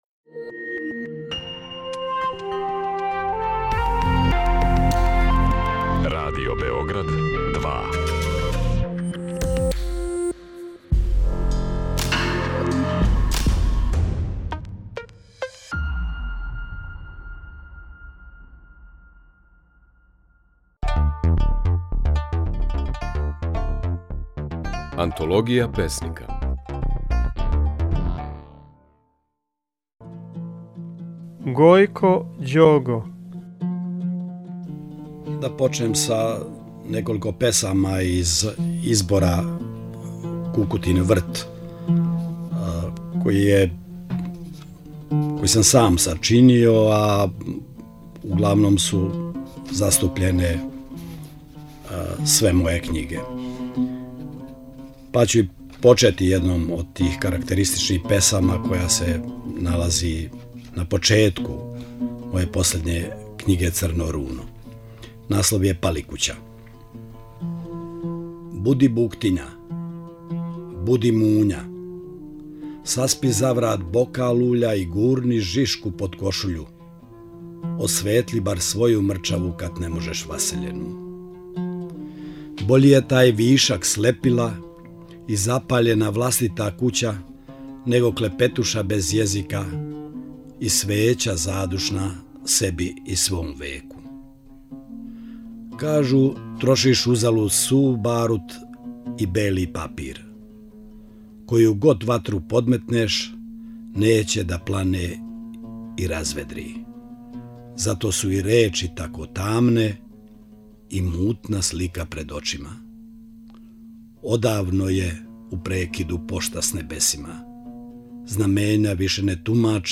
Можете чути како своје стихове говори Гојко Ђого.
Емитујемо снимке на којима своје стихове говоре наши познати песници